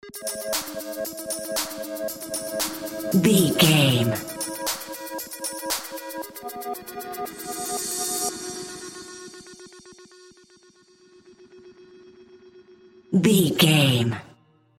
Aeolian/Minor
ethereal
dreamy
cheerful/happy
groovy
synthesiser
drum machine
house
electro dance
techno
trance
synth leads
synth bass
upbeat